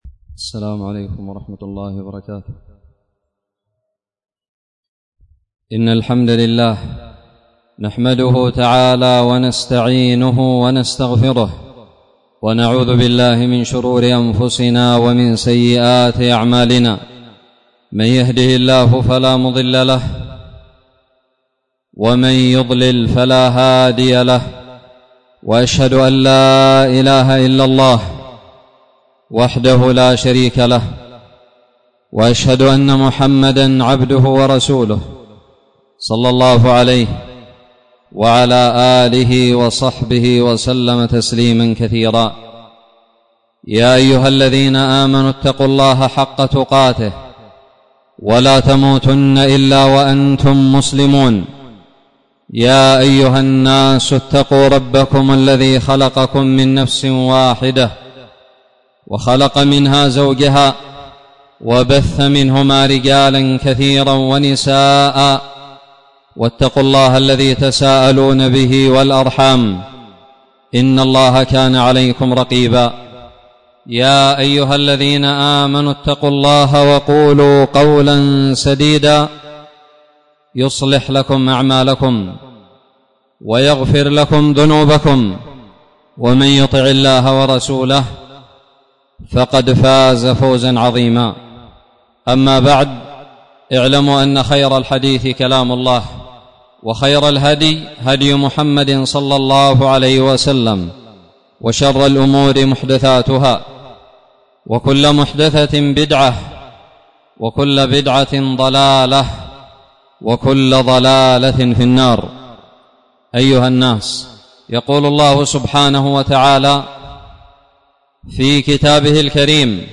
خطب الجمعة
ألقيت بدار الحديث السلفية للعلوم الشرعية بالضالع في 23 محرم 1442هــ